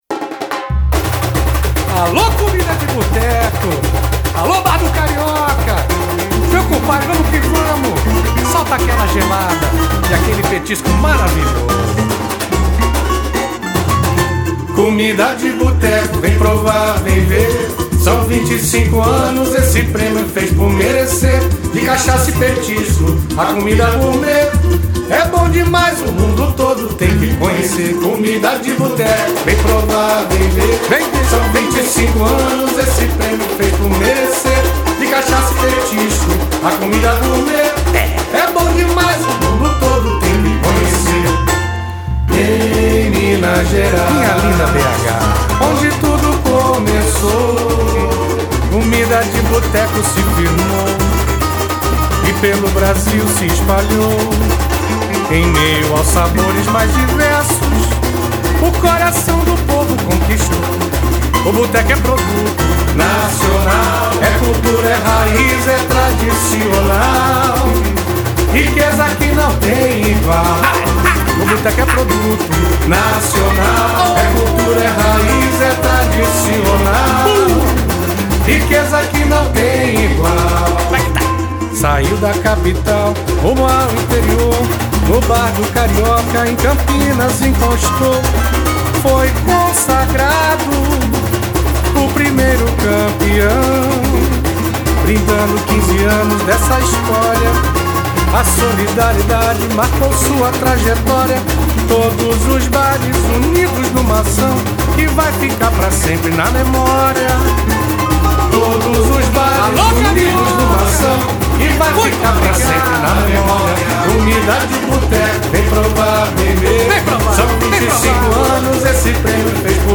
Cavacos
Violão 7 cordas
Coral